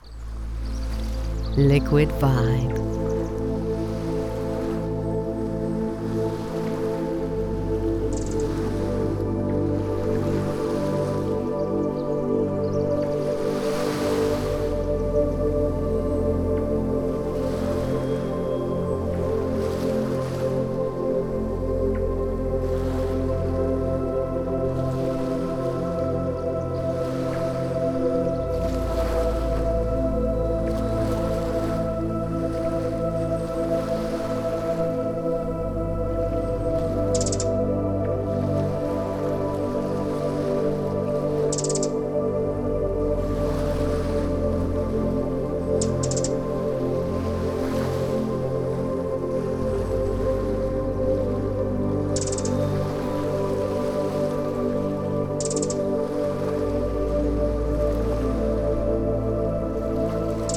Sea noise
Sea-noise-mp3cut.net_.wav